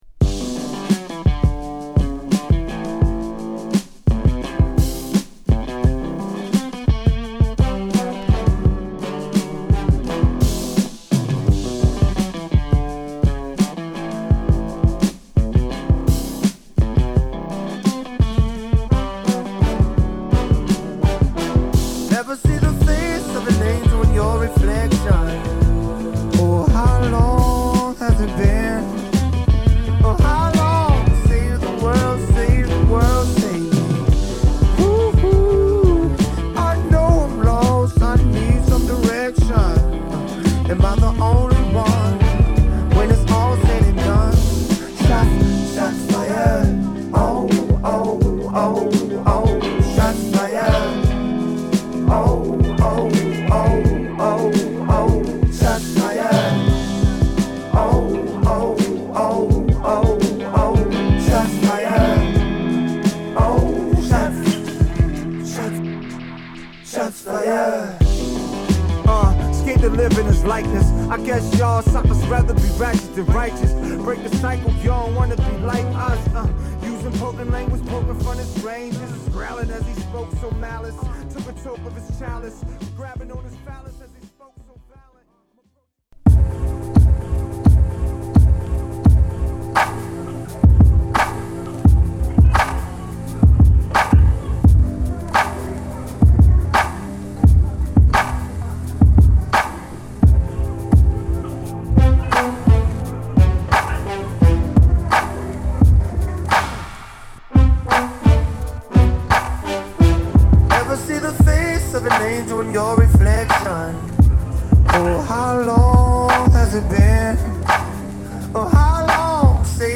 ロックなギターを絡めたエモーショナルなトラックにラップ＆ヴォーカルが乗る